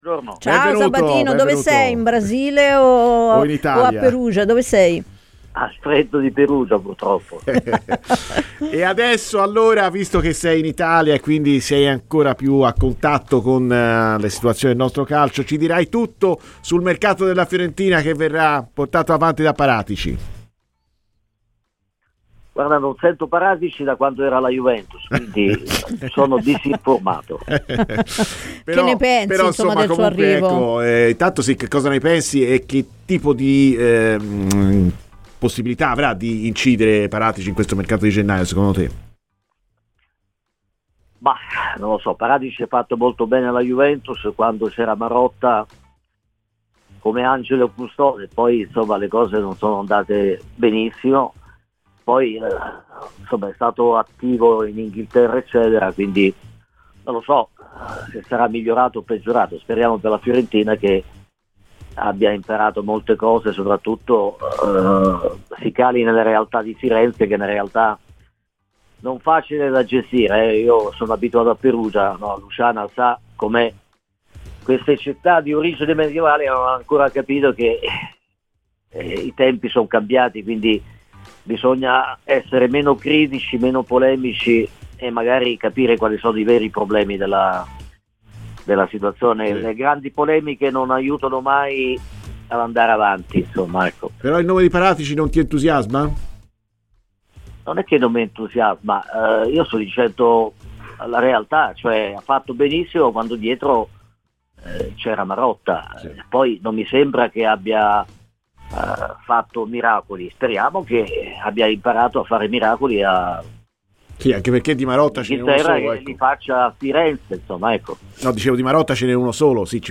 parlando in diretta su Radio FirenzeViola nel corso di "Viola Amore Mio"